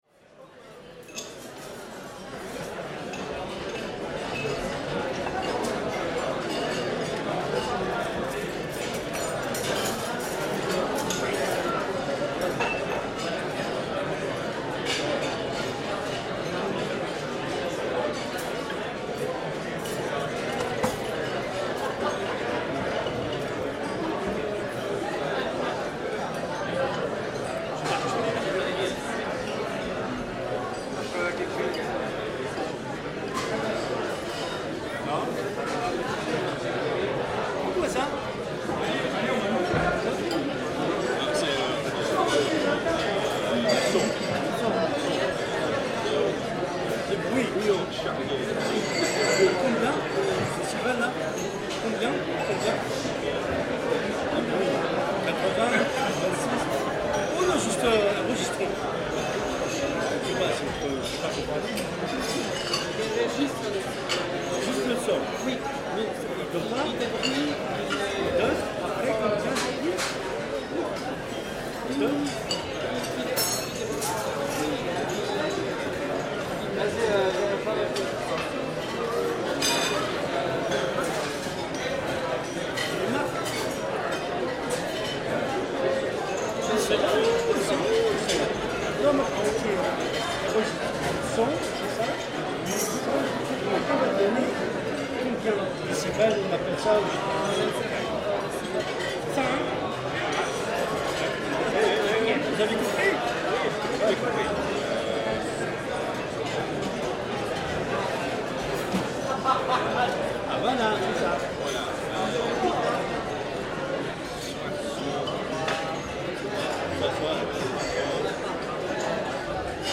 Lunchtime rush in a Parisian bouillon
The clatter, noise and hubbub of Bouillon Chartier, Paris, during the lunchtime rush hour - food, wine, serving staff shouting and the throb of conversation.